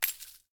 Bullet Shell Sounds
generic_leaves_4.ogg